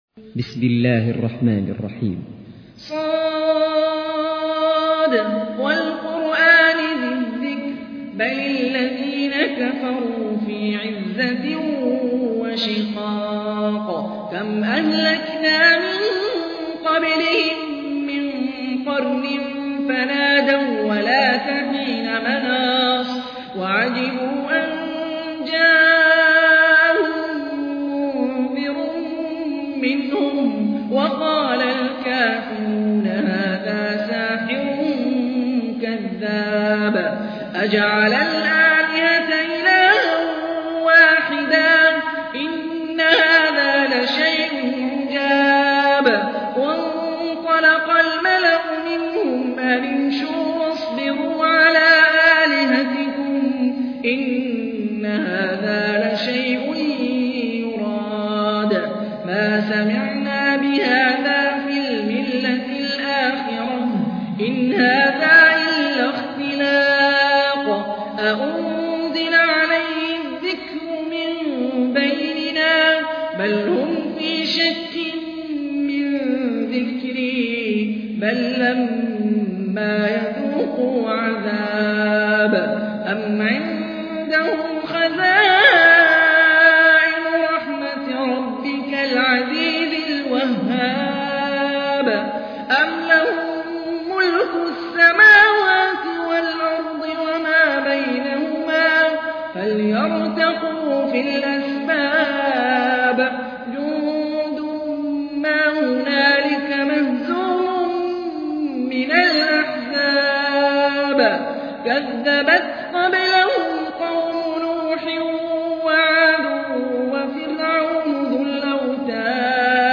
تحميل : 38. سورة ص / القارئ هاني الرفاعي / القرآن الكريم / موقع يا حسين